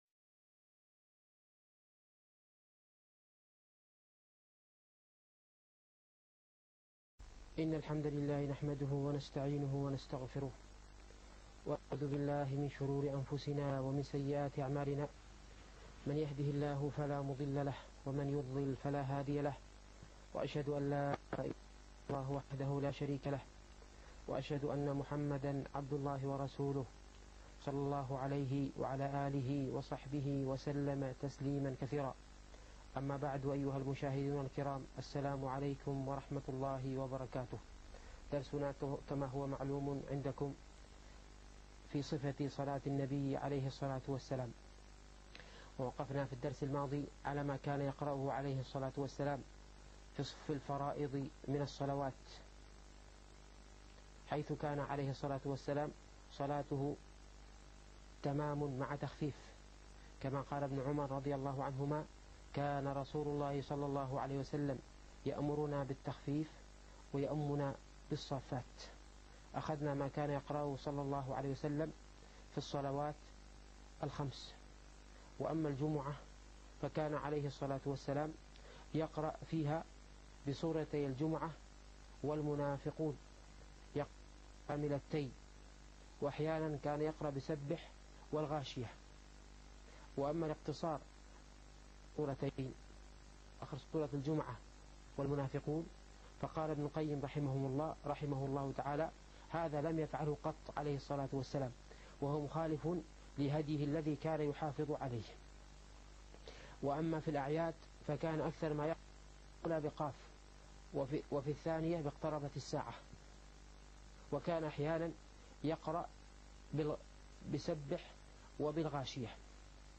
الفقه الميسر - الدرس الثاني والعشرون